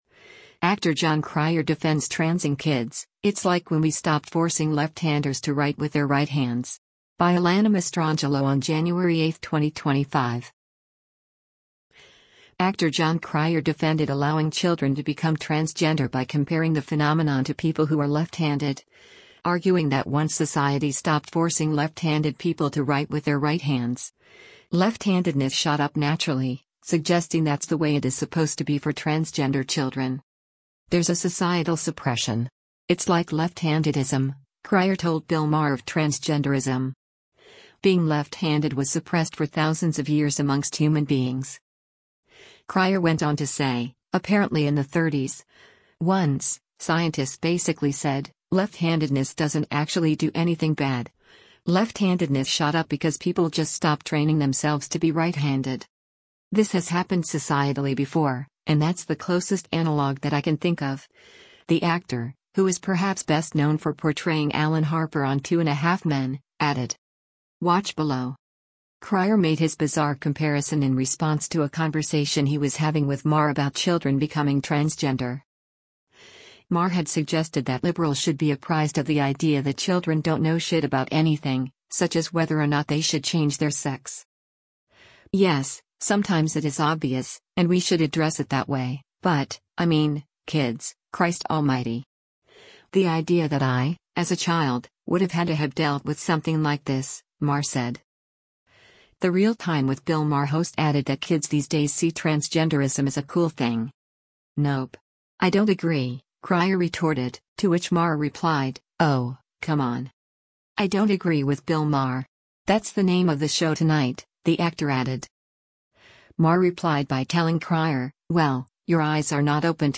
Cryer made his bizarre comparison in response to a conversation he was having with Maher about children becoming transgender.